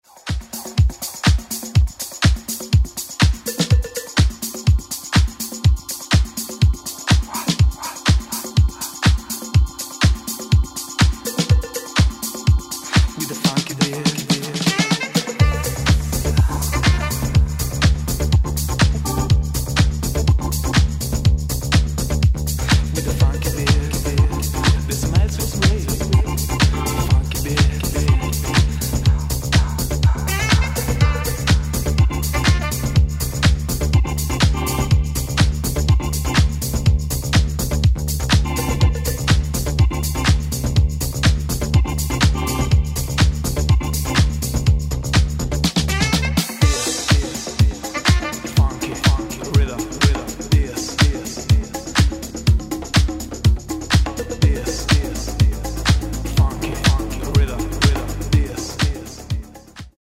reveal an unexpected falsetto